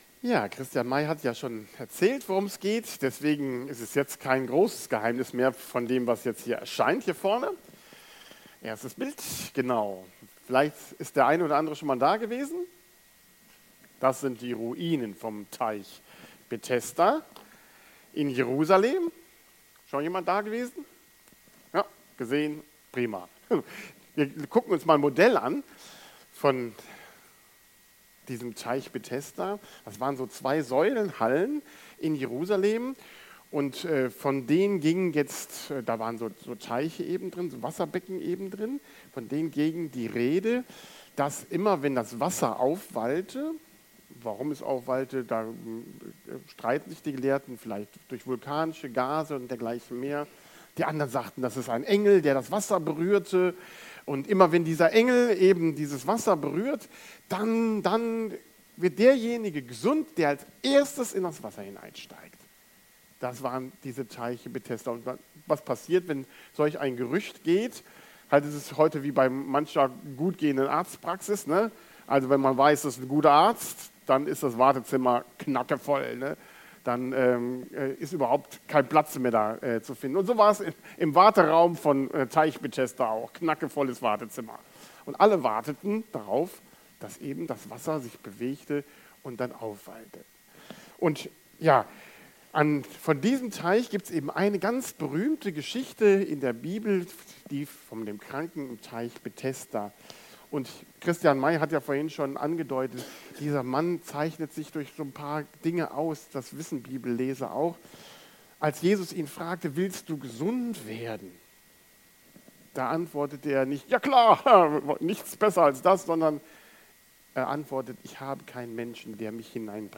Bible Text: Johannes 5,1-18 | Prediger/in